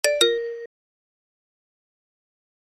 audio_end_session_notification.mp3